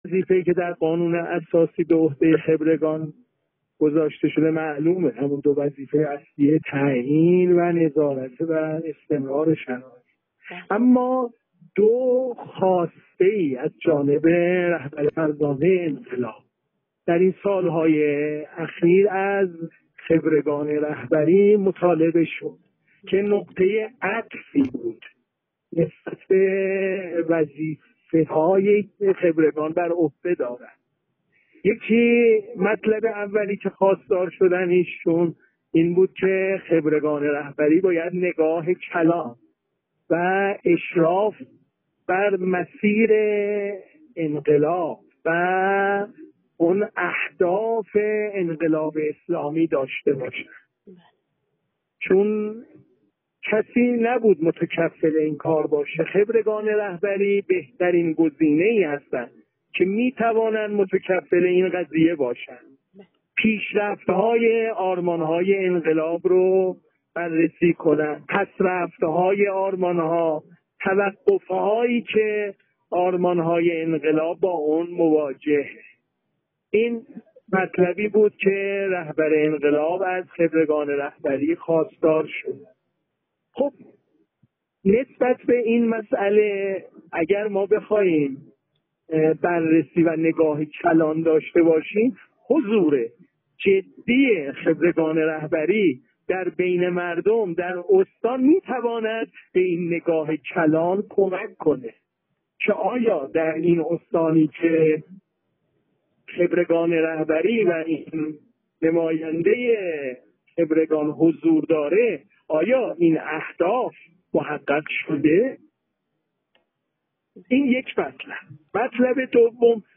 در گفت‌وگو با ایکنا مطرح شد